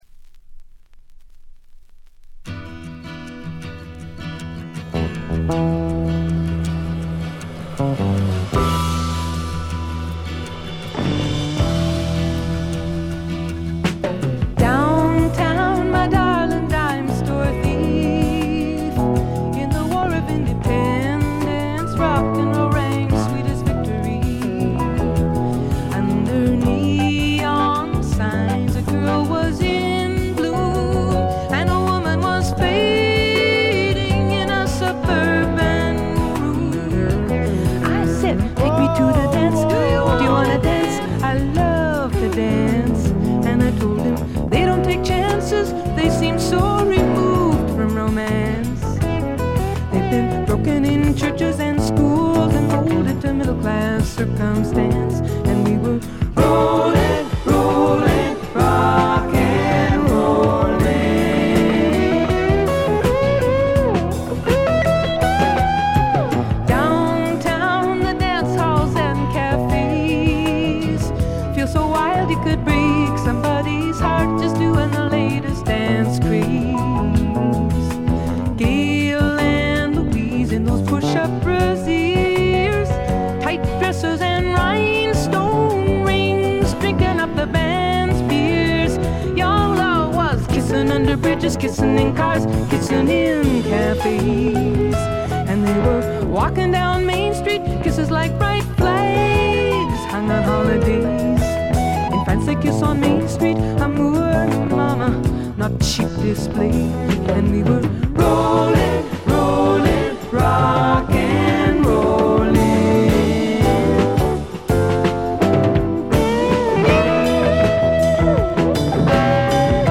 微細なバックグラウンドノイズ程度でほとんどノイズ感無し。
ここからが本格的なジャズ／フュージョン路線ということでフォーキーぽさは完全になくなりました。
試聴曲は現品からの取り込み音源です。